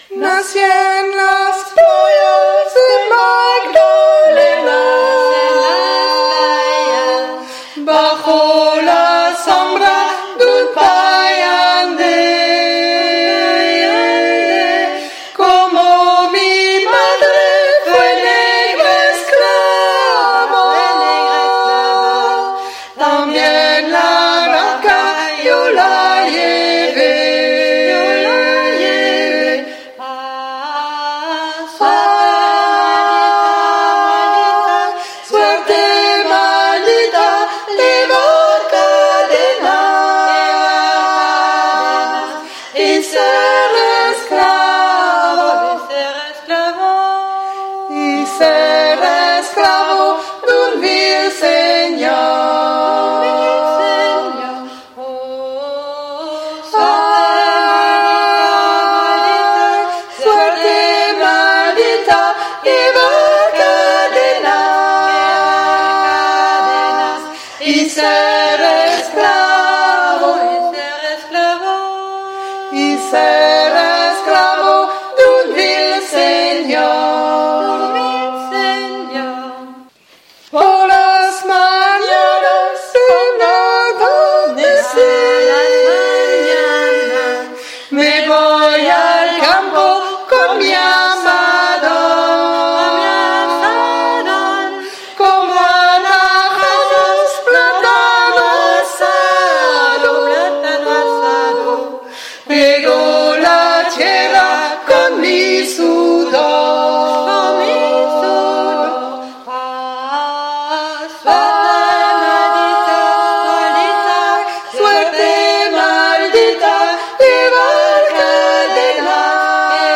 les sons pour apprendre, par vos cheffes préférées